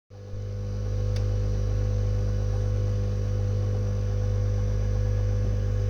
Ensuite, un bruit de moteur inhabituel (cf audio) et des passages silencieux mais aucun bruit de brassage.